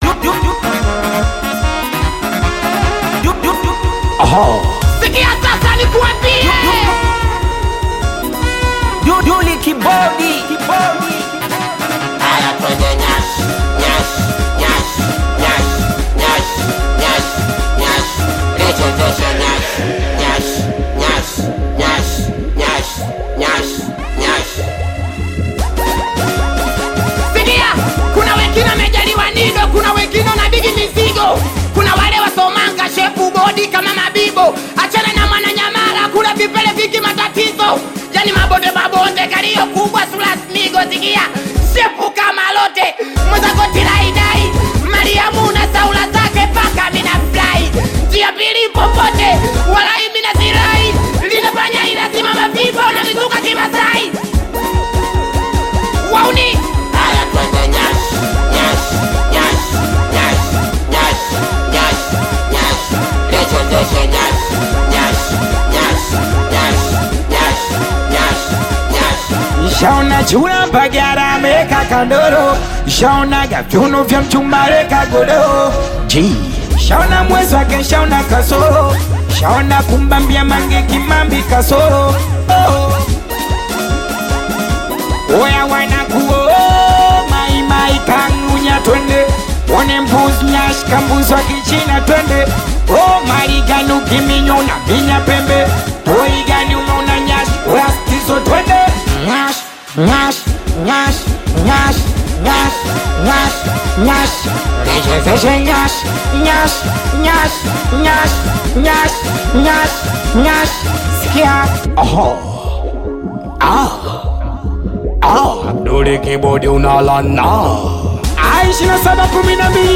Singeli music track
Singeli